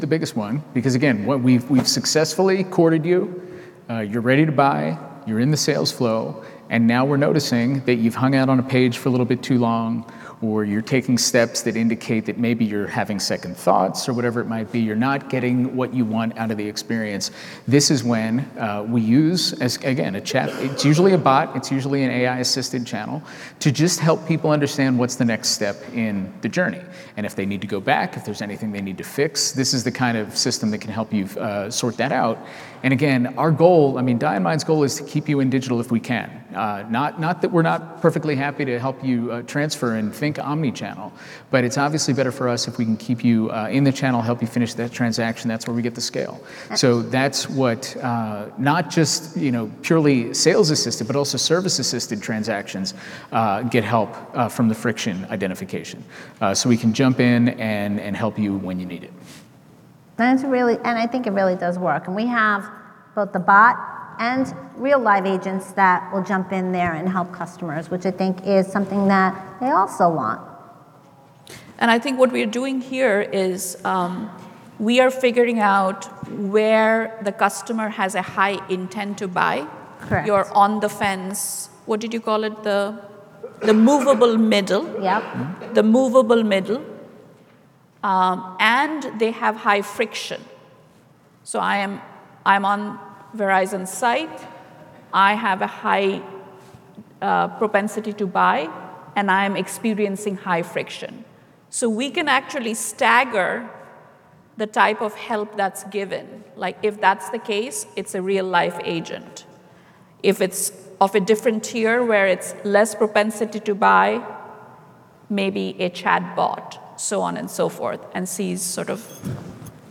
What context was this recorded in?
Adobe Summit